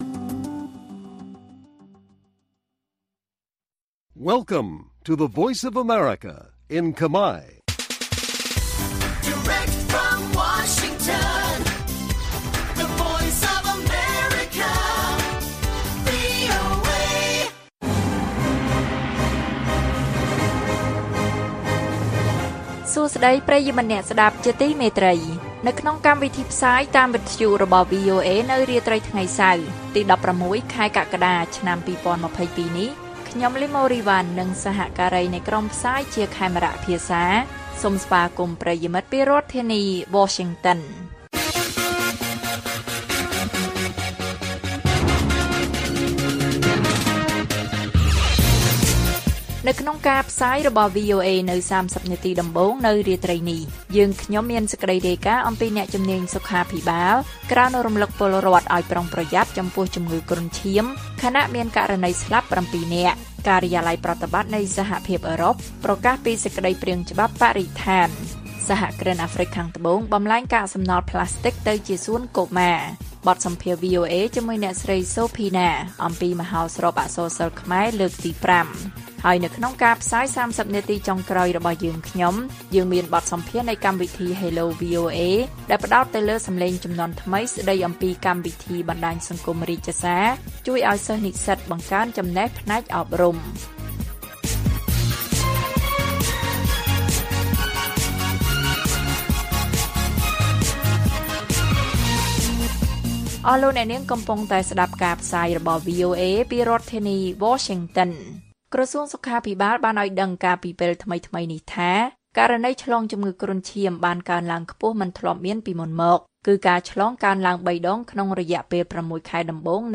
ព័ត៌មាននៅថ្ងៃនេះមានដូចជា អ្នកជំនាញសុខាភិបាលក្រើនរំឭកពលរដ្ឋឲ្យប្រុងប្រយ័ត្នចំពោះជំងឺគ្រុនឈាម ខណៈមានករណីស្លាប់៧នាក់។ កិច្ចសម្ភាសន៍នៃកម្មវិធី Hello VOA ស្តីអំពីកម្មវិធីបណ្តាញសង្គម «រាជសារ» ជួយឱ្យសិស្ស-និស្សិតបង្កើនចំណេះផ្នែកអប់រំ និងព័ត៌មានផ្សេងៗទៀត៕